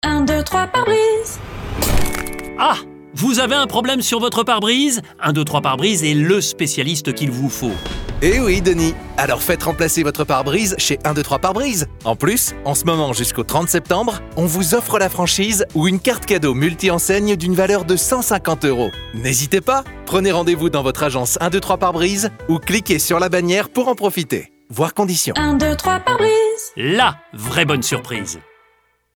Spot Radio